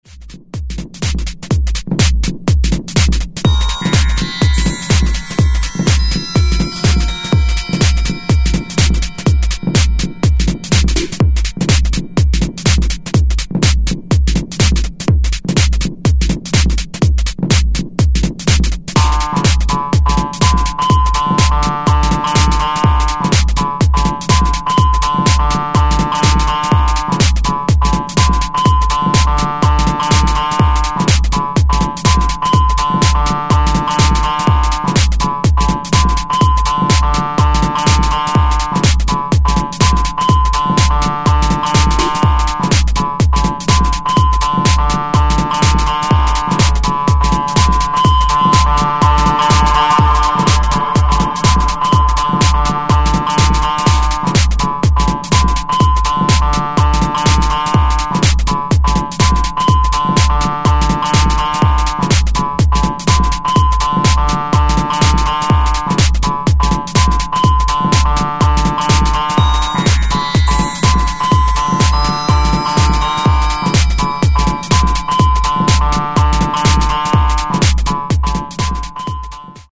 Minimal tech/house for the sleepless.